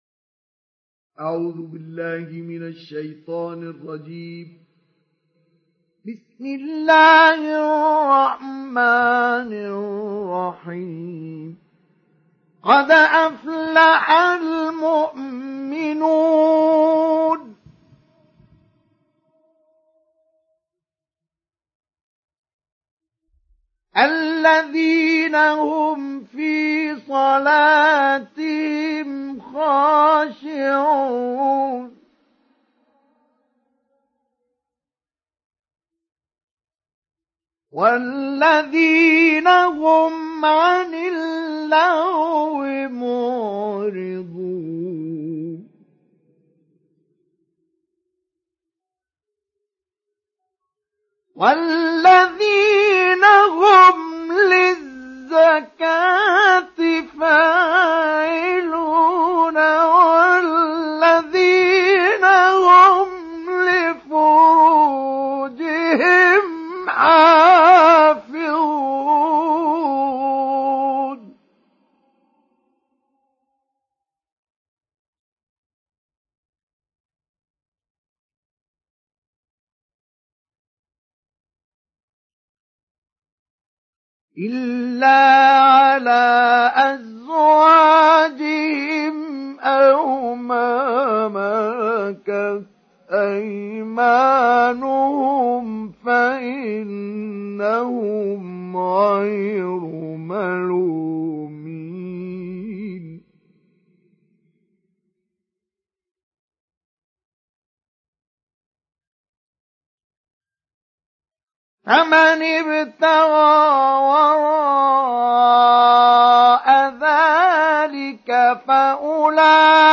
سُورَةُ المُؤۡمِنُونَ بصوت الشيخ مصطفى اسماعيل